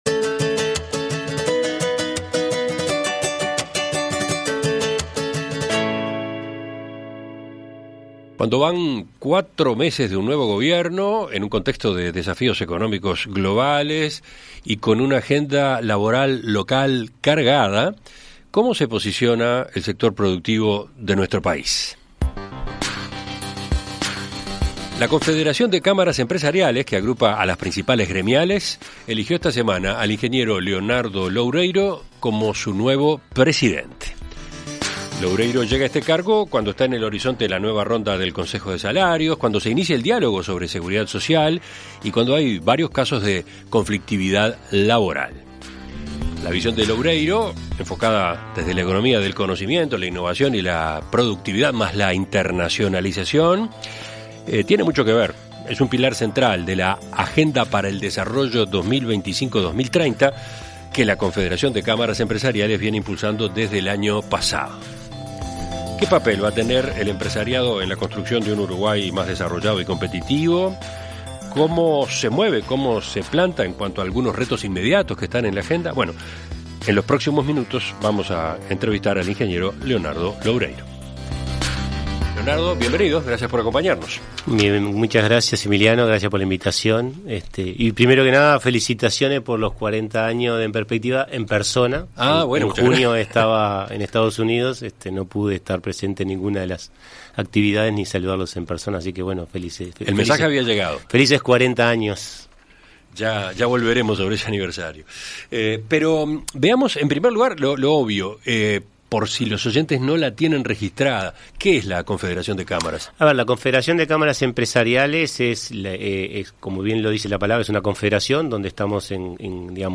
En Perspectiva Zona 1 – Entrevista Central